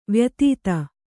♪ vyatīta